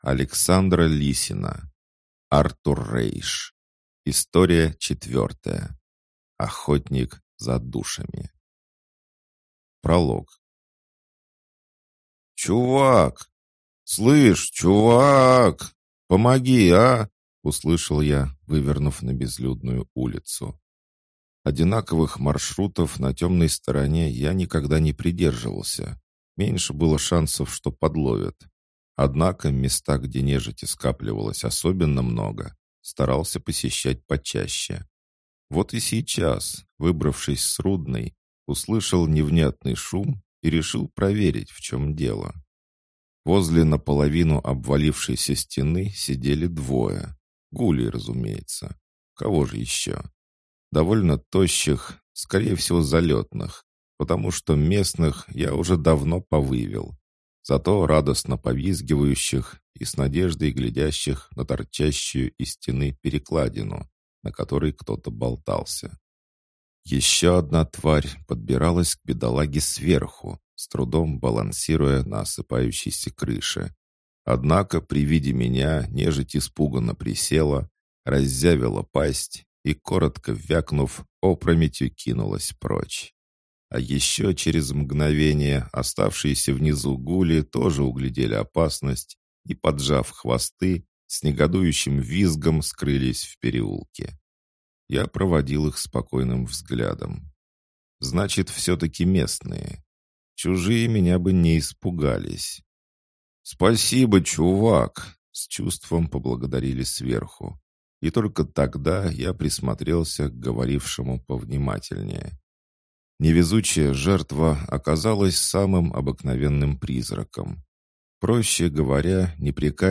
Аудиокнига Охотник за душами | Библиотека аудиокниг
Прослушать и бесплатно скачать фрагмент аудиокниги